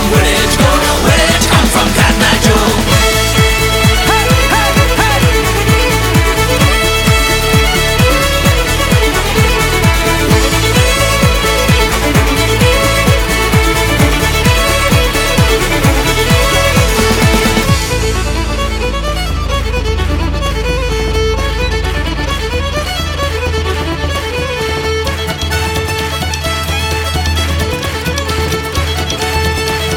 Жанр: Альтернатива / Фолк-рок